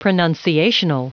Prononciation du mot pronunciational en anglais (fichier audio)
Prononciation du mot : pronunciational
pronunciational.wav